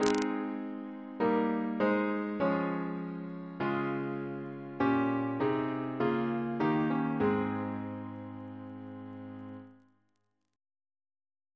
Single chant in A♭ Composer: George A. Macfarren (1813-1887) Reference psalters: ACB: 47; CWP: 17; H1940: 606 676; H1982: S36 S418; OCB: 78; PP/SNCB: 36; RSCM: 193